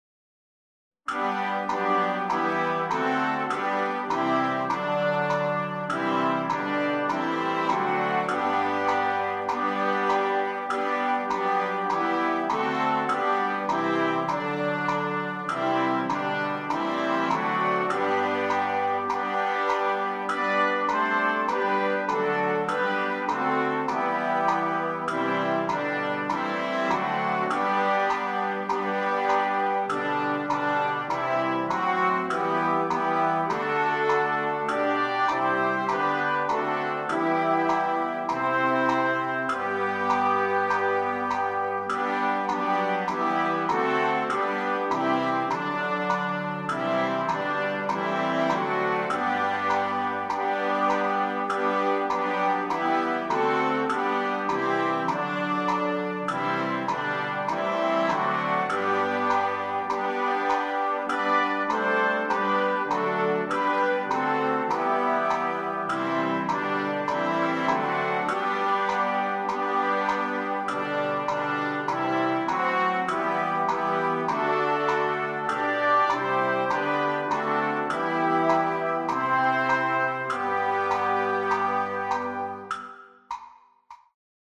SOUND RECORDINGS/PRACTICE TRACKS:
Good King Wenceslas w Click  Track.mp3